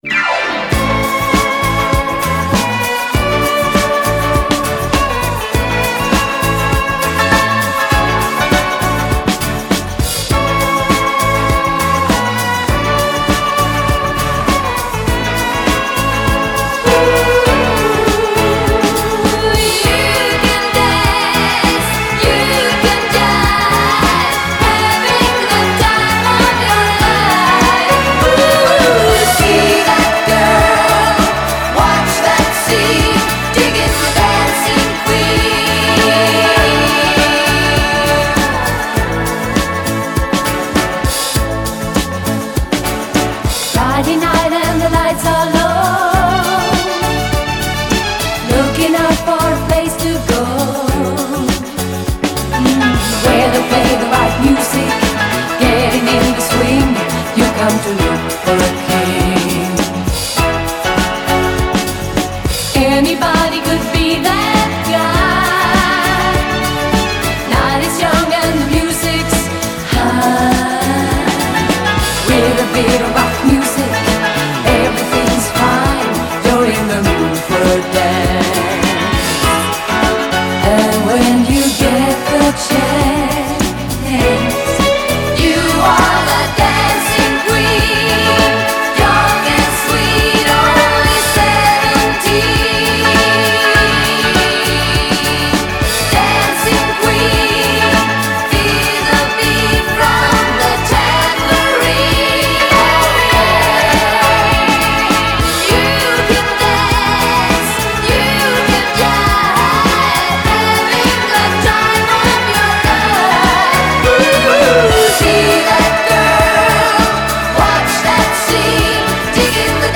Pop, Disco, Europop